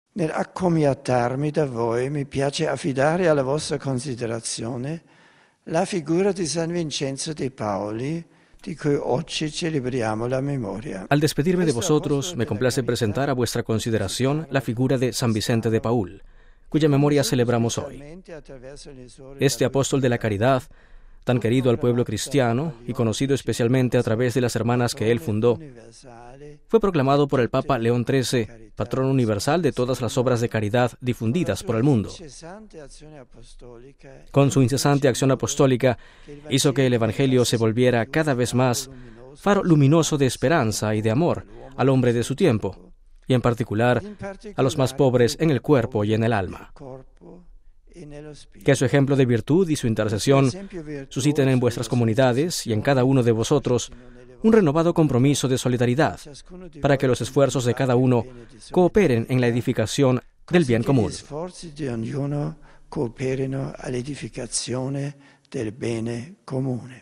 Lunes, 27 sep (RV).- Benedicto XVI - que volverá a Roma el próximo jueves, como anunció ayer él mismo - se ha despedido esta mañana de Castelgandolfo, saludando y agradeciendo a los representantes de las comunidades eclesial y civil de este amena localidad, tan entrañable para él, donde la Providencia le concede transcurrir cada año una estancia serena y provechosa.
Benedicto XVI se ha despedido de esta localidad volviendo a recordar el luminoso testimonio de caridad cristiana de san Vicente de Paúl: